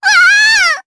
Rehartna-Vox_Damage_jp_03_b.wav